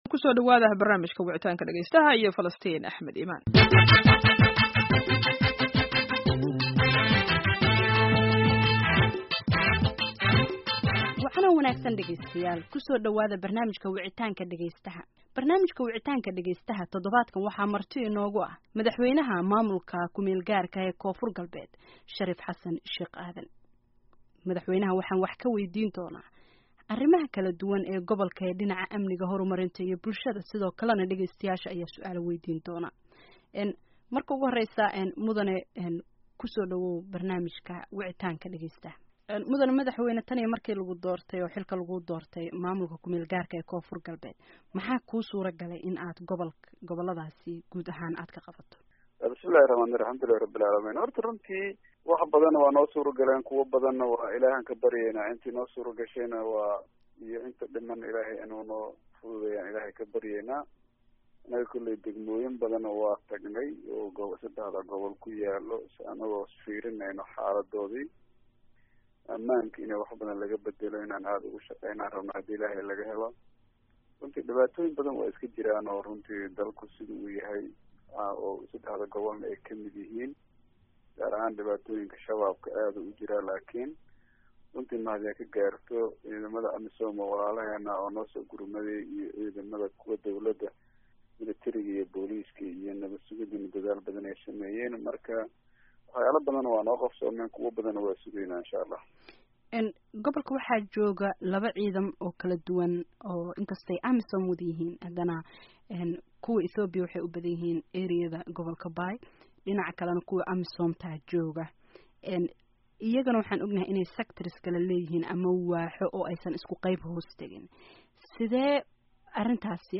Baydhaba (INO)-Barnaamijka Wicitaanka Dhageystaha VOA ee toddobaadkan waxaa marti ku ah madaxweynah Dowladda Koonfur-galbeed Shariif Xasan Sheekh Aadan.
Barnaamijka-Wicitaanka-Dhageystaha-VOA.mp3